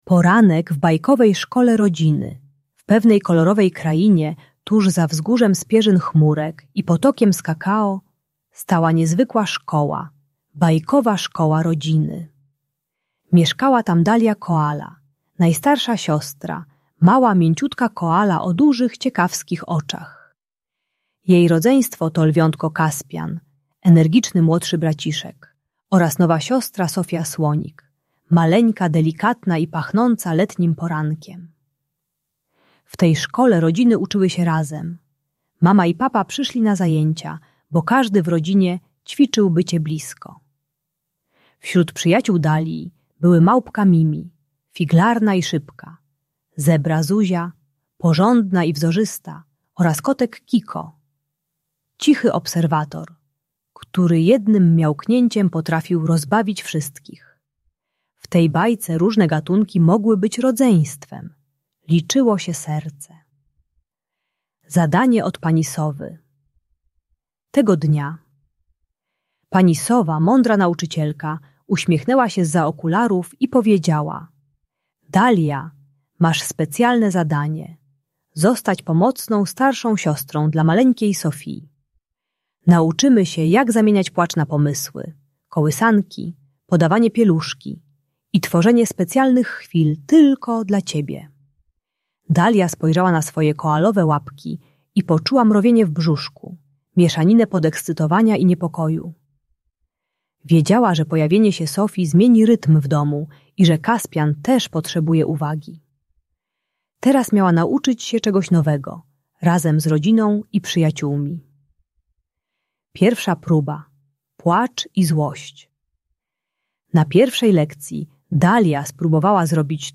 Bajkowa Szkoła Rodziny: Historia Dalii i Sofii - Rodzeństwo | Audiobajka